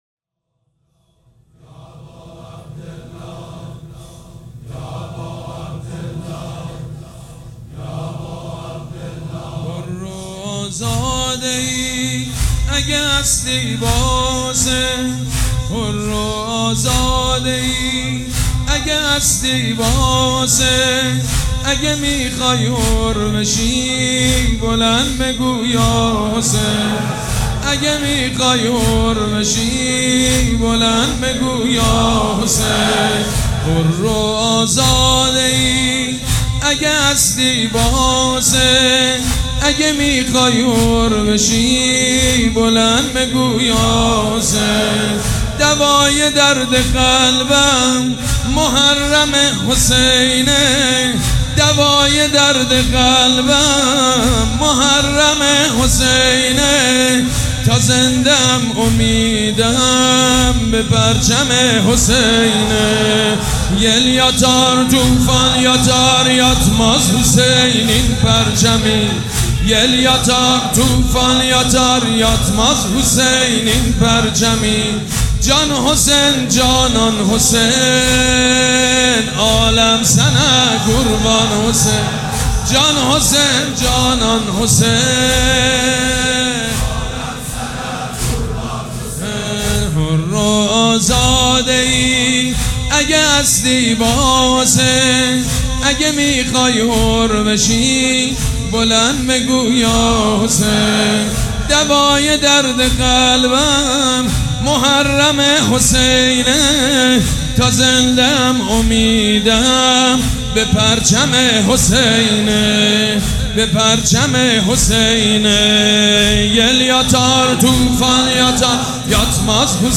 صوت مداحی سید مجید بنی‌فاطمه در سومین شب از ماه محرم را بشنوید.
سید مجید بنی‌فاطمه مداح اهل‌بیت(ع) در سومین شب از مراسم عزاداری حضرت سیدالشهداء(ع) در حسینیه ریحانةالحسین(ع) که با حضور پرشور عاشقان و دلدادگان حسینی برگزار شد، به مدیحه‌سرایی پرداخت که صوت آن را در ادامه می‌شنوید.